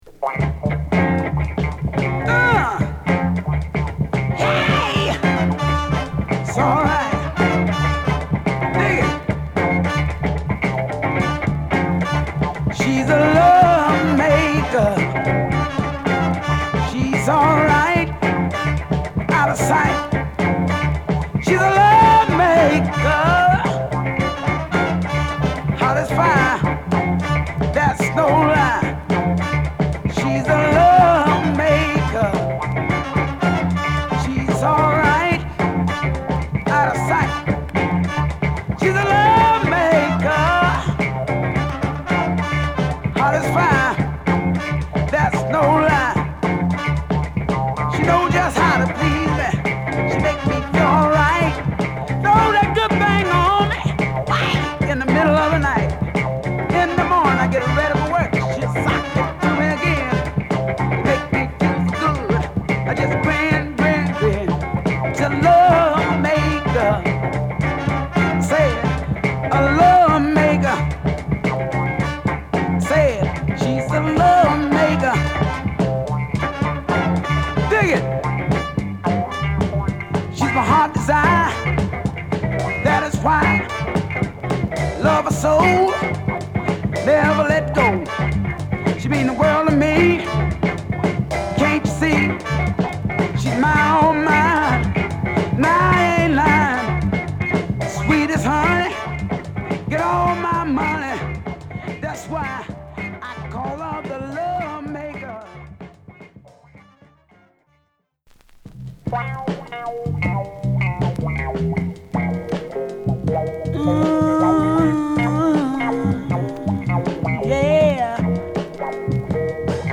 ロウなサウンドで73年のヴァージョンよりもファンク度高い1曲！